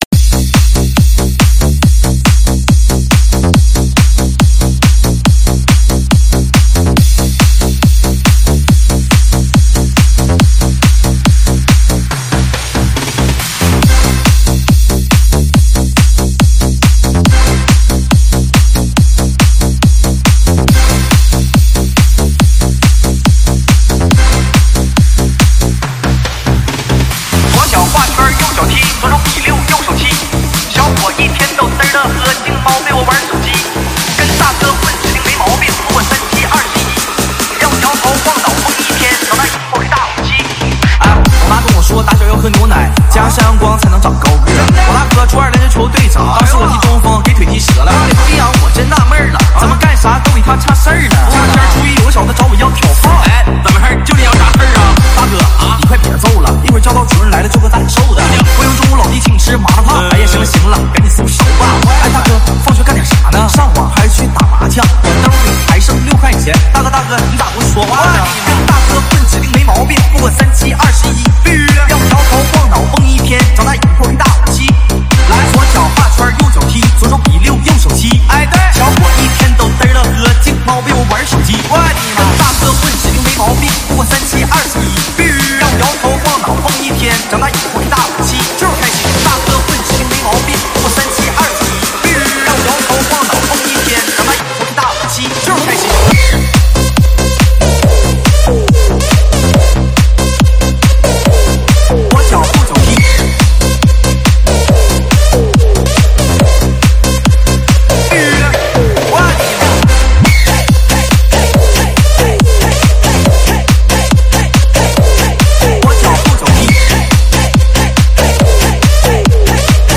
试听文件为低音质，下载后为无水印高音质文件 M币 8 超级会员 M币 4 购买下载 您当前未登录！